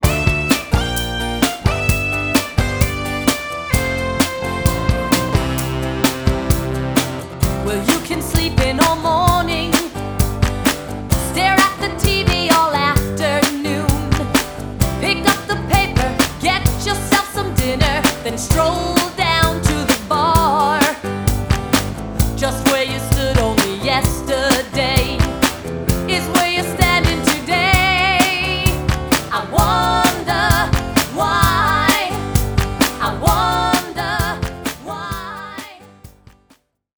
Vocals
Guitar, bass, keyboards, and drums